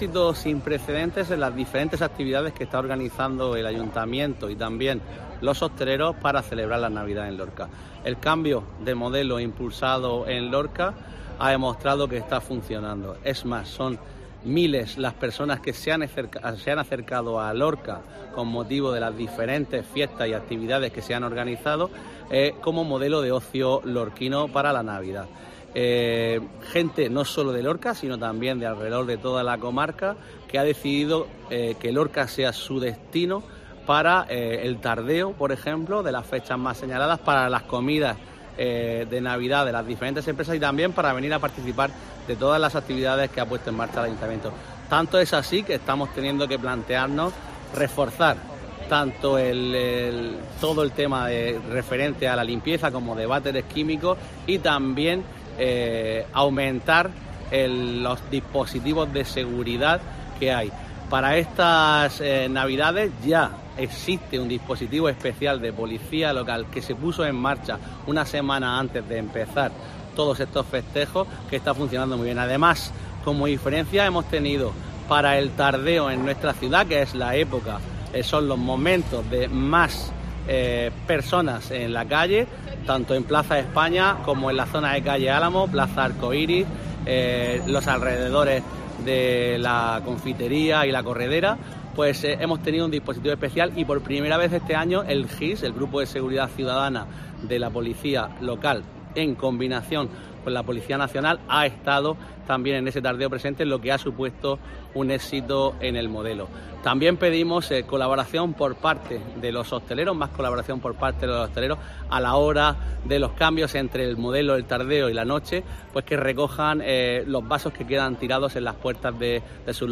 Juan Miguel Bayonas, edil Seguridad Ciudadana de Ayuntamiento de Lorca